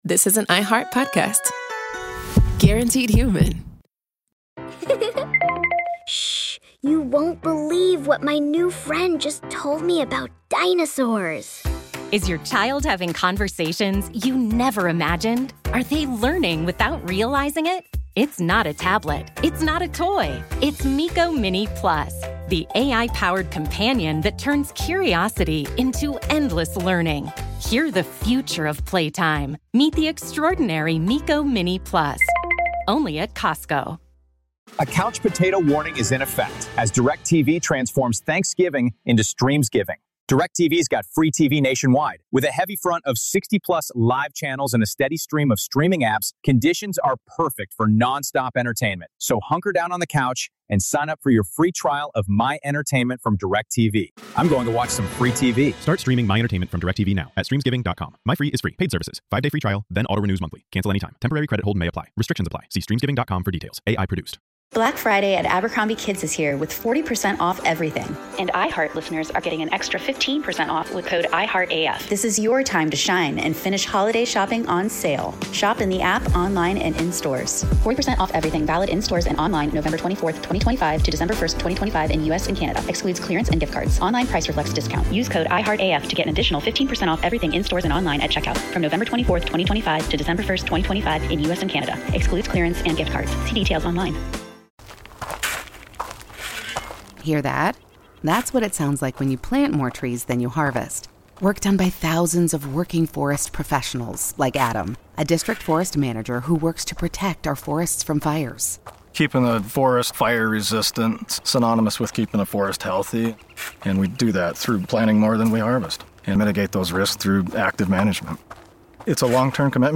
sat down with Brett in his Hattiesburg, Mississippi, home for part five of our five-part series. In this conversation, the long-time Green Bay Packers star and Super Bowl champion reflects on the moments, challenges, and memories that shaped his life on and off the field.